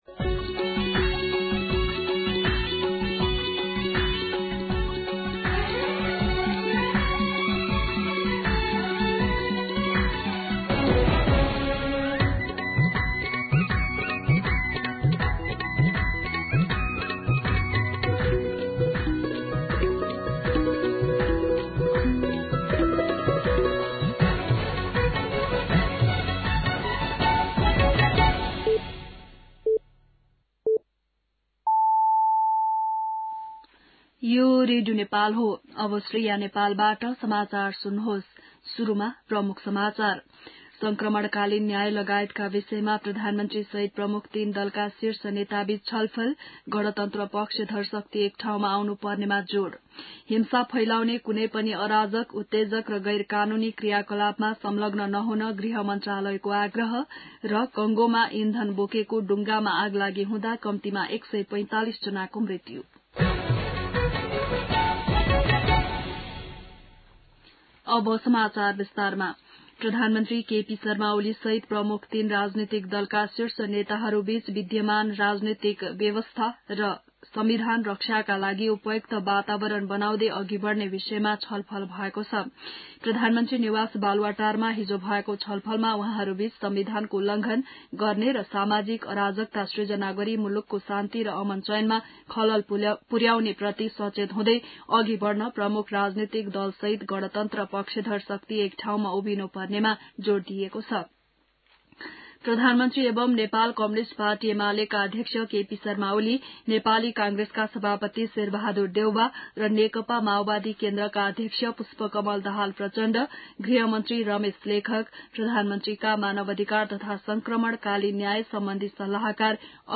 बिहान ९ बजेको नेपाली समाचार : ७ वैशाख , २०८२
9am-News-01-7.mp3